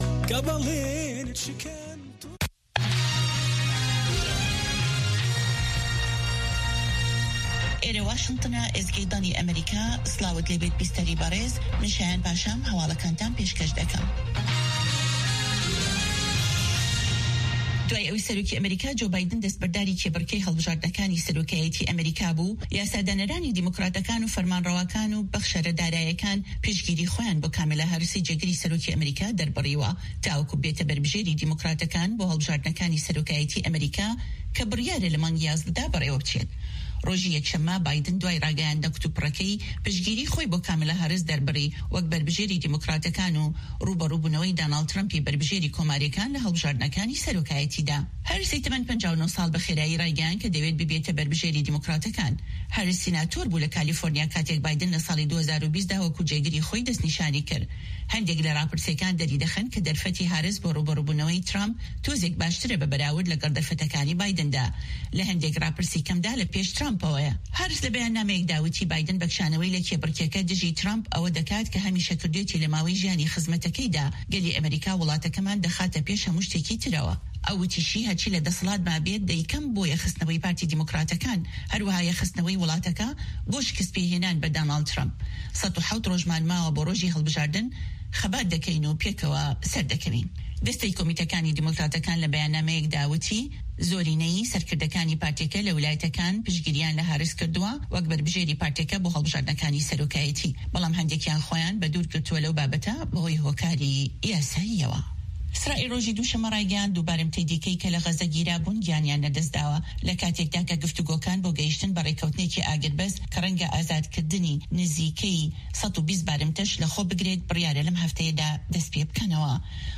Nûçeyên 3’yê paşnîvro